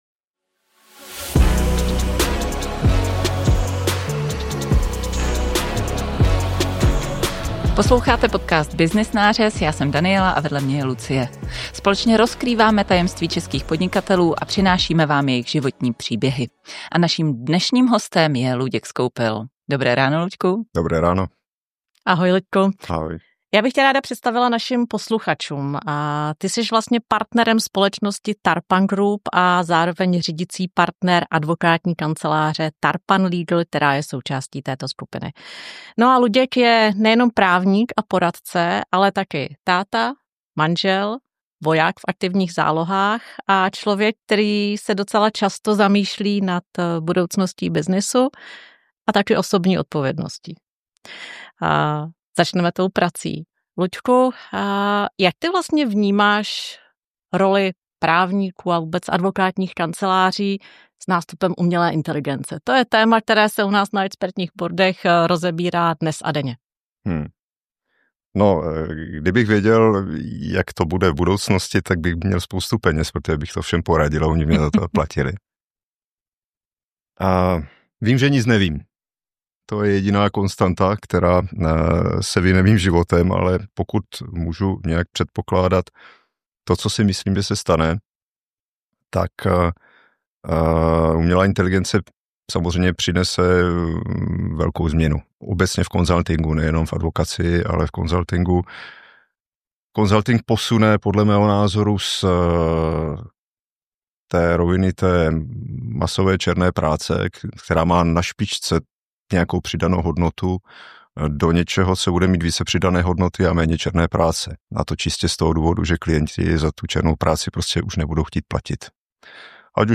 🎧 Celý rozhovor si můžete poslechnout v podcastu Byznys nářez .